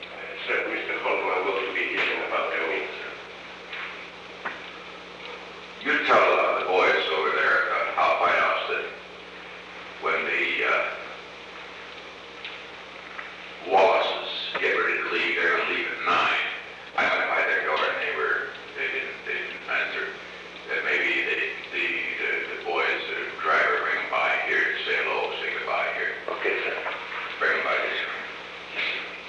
Recording Device: Oval Office